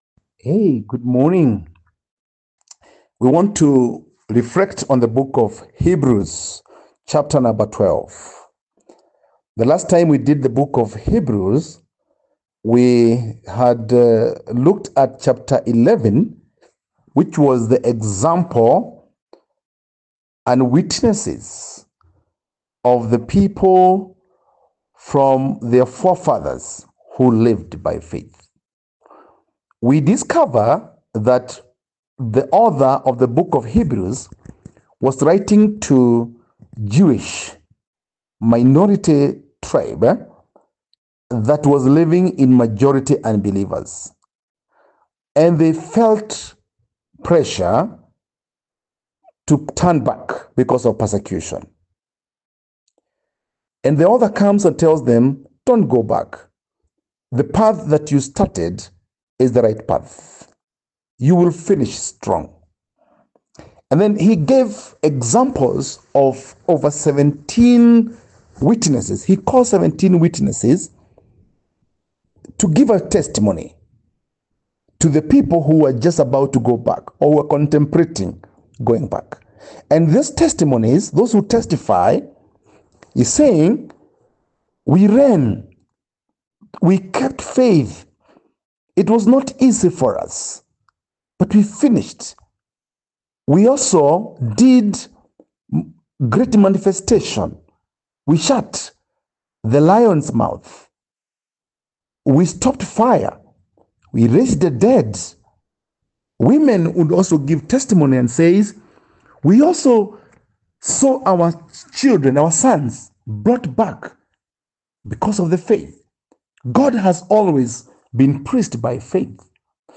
Post Lesson Teaching Summary